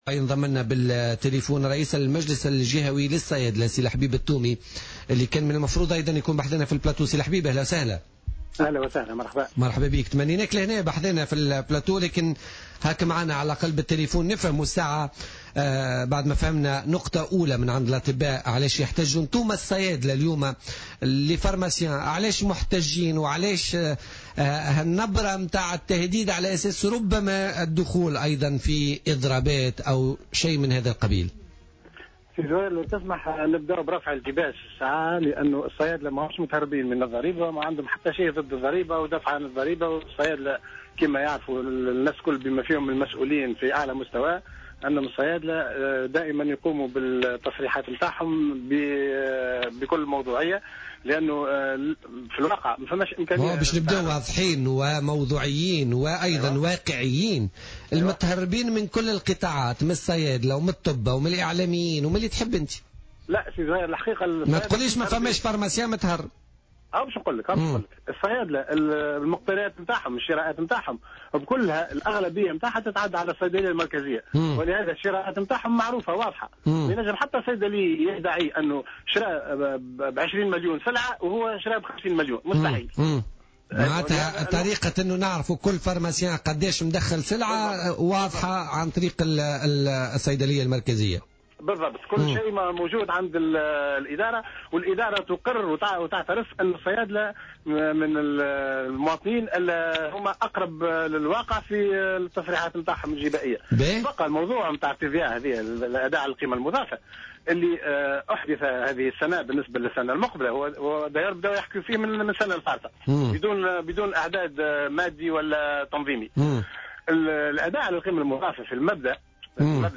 وأضاف في مداخلة له اليوم في برنامج "بوليتيكا" أن فرض هذه الضريبة سيثقل كاهل أصحاب الصيدليات، وسيجبر ما بين 400 و500 صيدلية إلى غلق أبوابها.